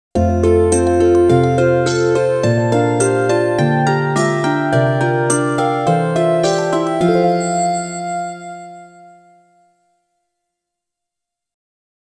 少々長い曲もありますが、発車ベルとしての機能を果たせるように心掛け製作しました。